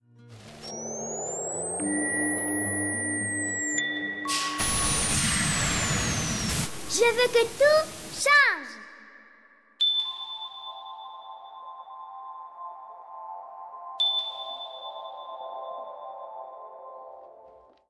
extrait du générique de l’émission